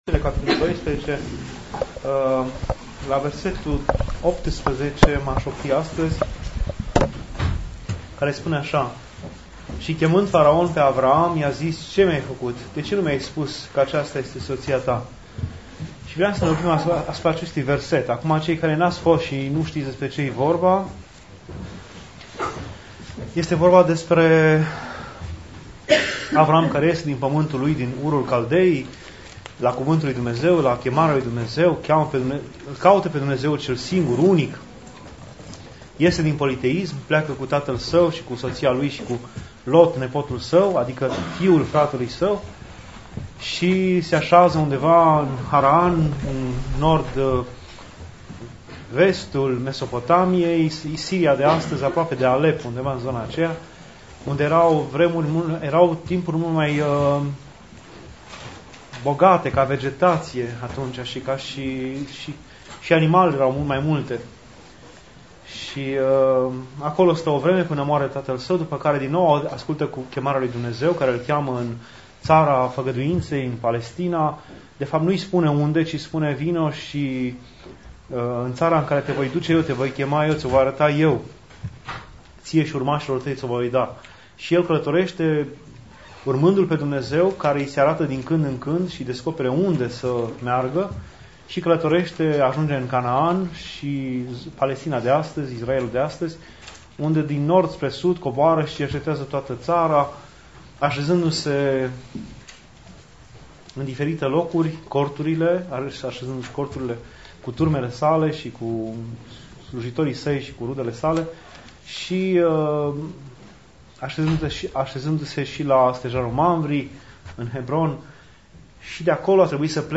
Scurt cuvânt după Dumnezeiasca Liturghie